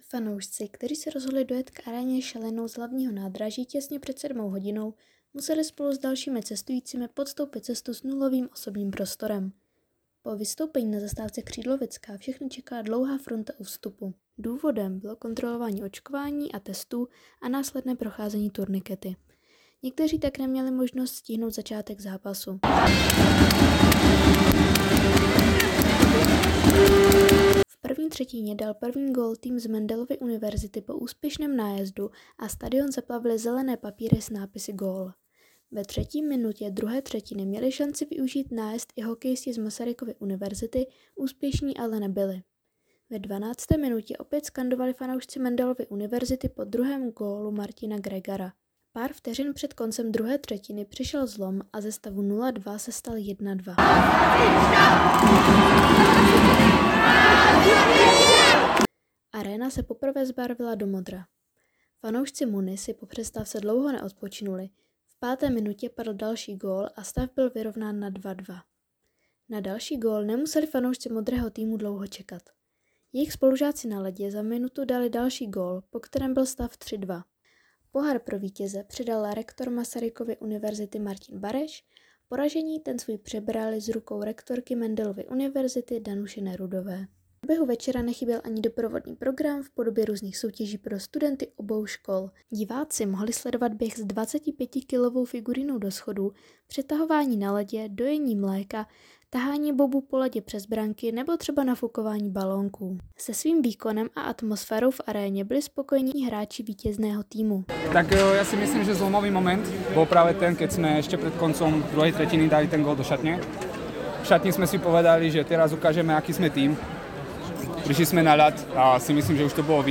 Reportáž MUNI hokej.wav